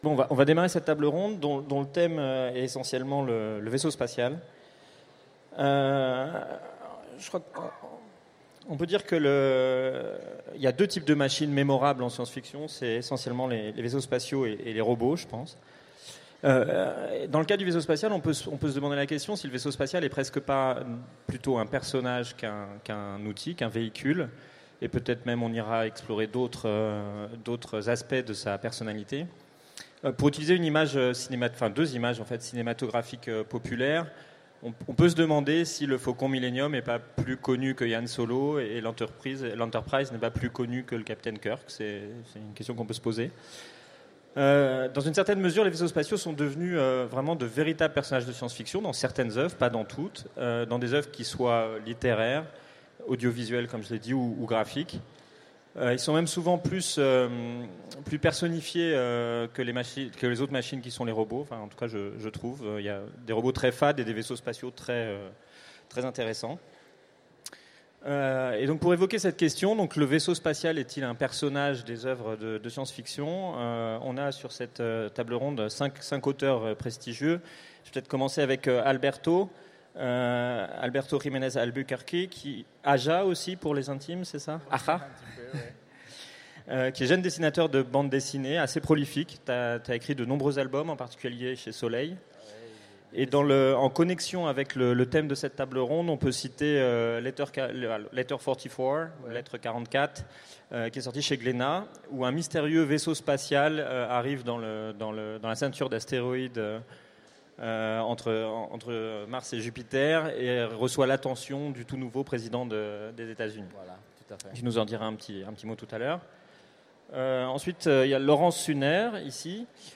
Utopiales 2016 : Conférence Le vaisseau spatial comme personnage